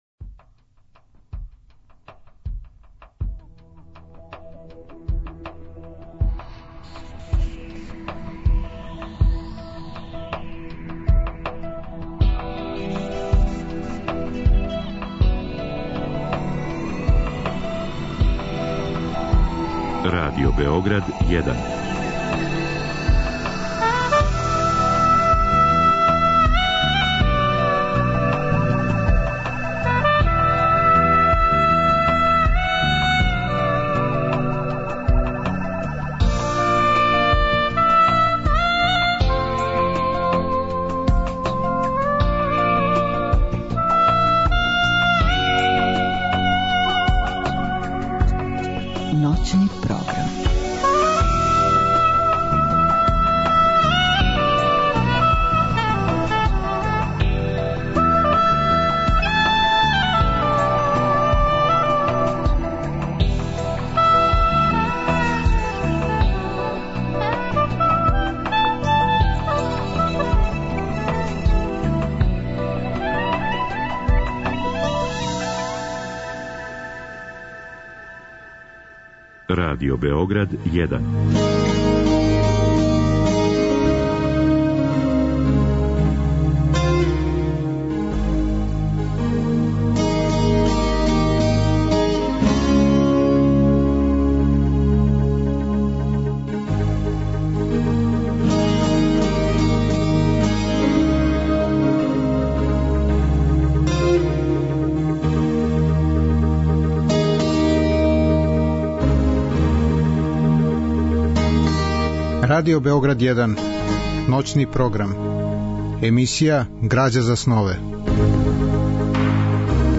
Разговор и добра музика требало би да кроз ову емисију и сами постану грађа за снове
Радио-драма је реализована 1996. године у продукцији Драмског програма Радио Београда.